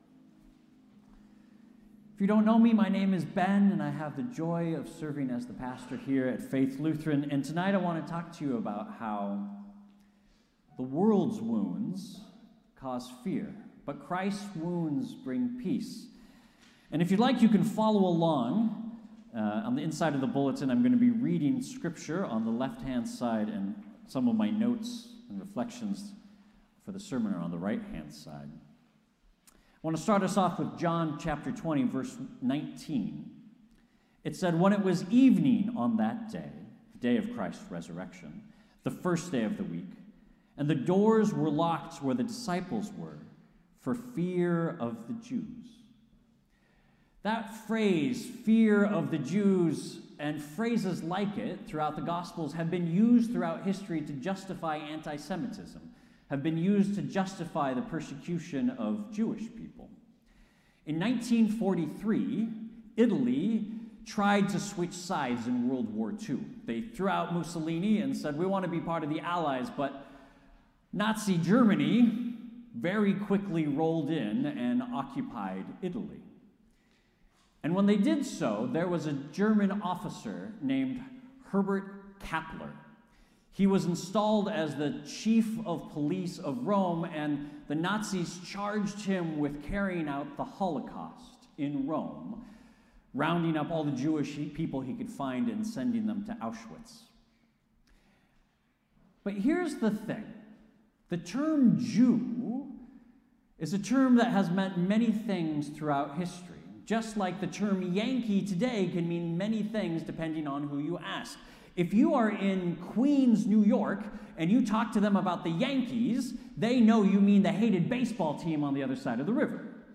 Faith Lutheran Church Sermons Current Sermon 2nd Sunday of Easter The world's wounds cause fear; Christ's wounds bring peace.